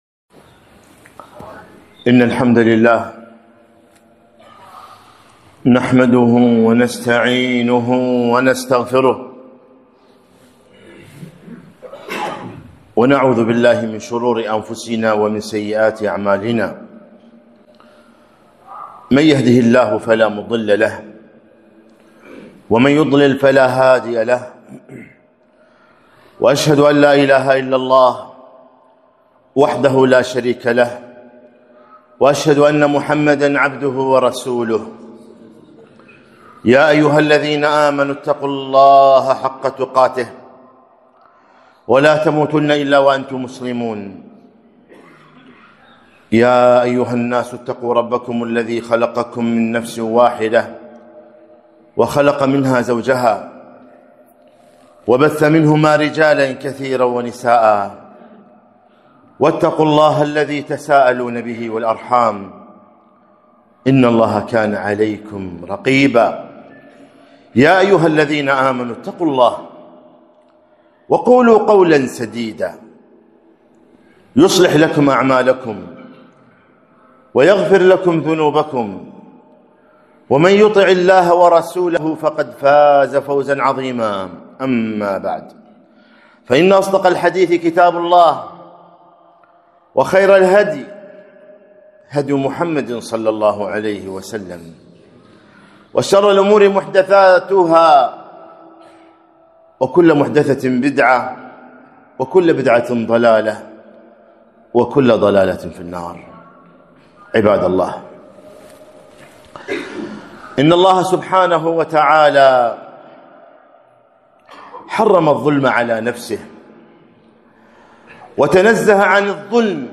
خطبة - الظلم ظلمات يوم القيامة